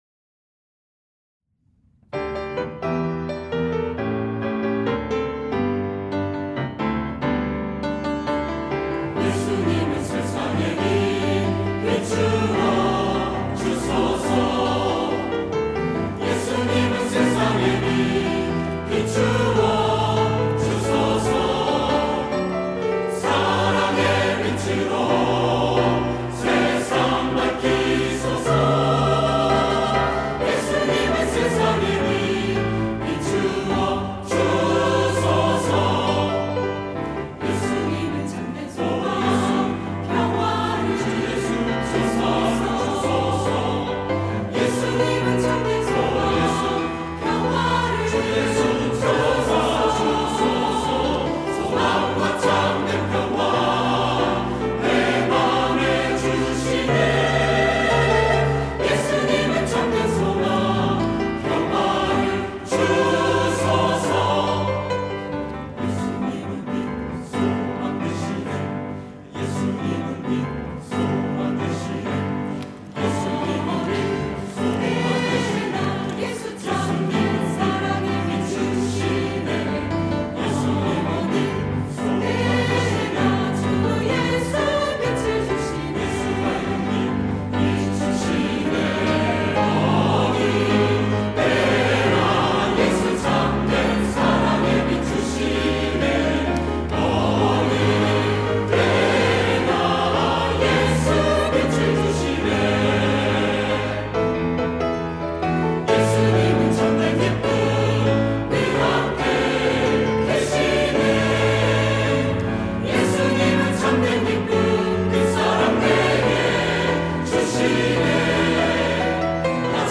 할렐루야(주일2부) - 예수님은 세상의 빛
찬양대 할렐루야